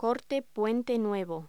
Locución: Corte puente nuevo
voz